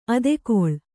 ♪ adekoḷ